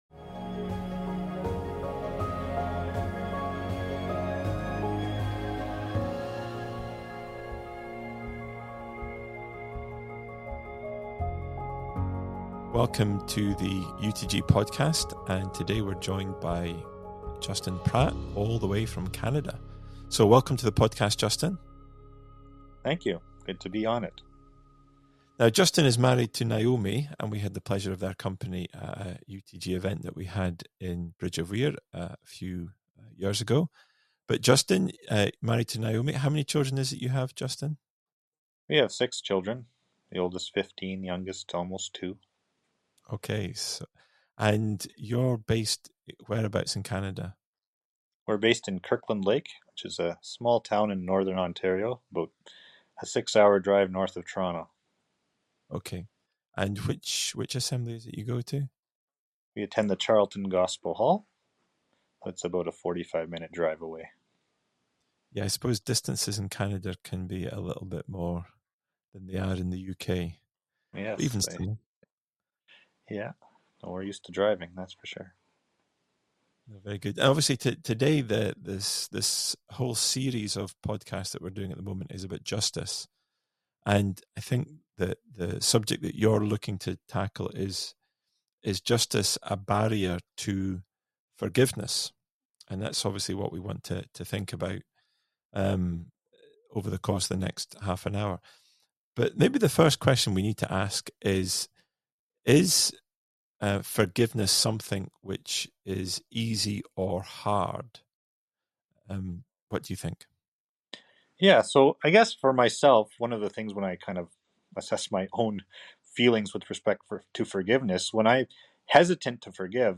This is part 4 in a series of conversations about Justice.